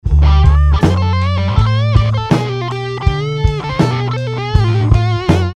Advanced Blues Lick_12 – Guitar Alliance